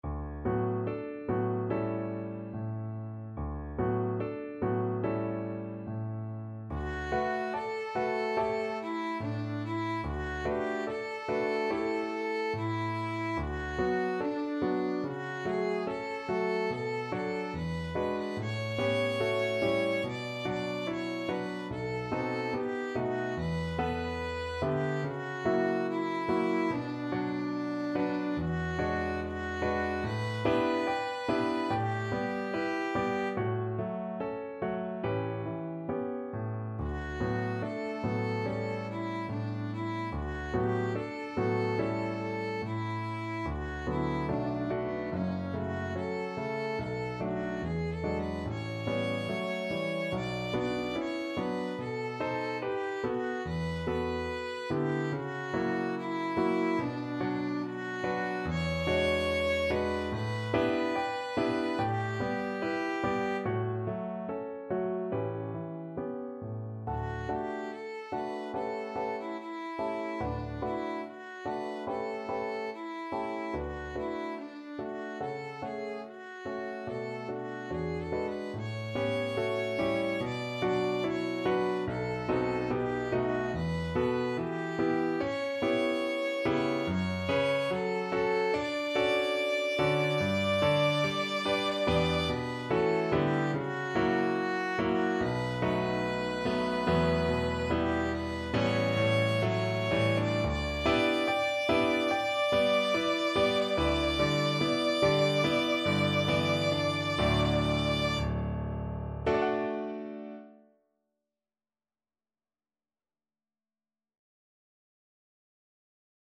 Violin version
4/4 (View more 4/4 Music)
~ = 72 In moderate time
Classical (View more Classical Violin Music)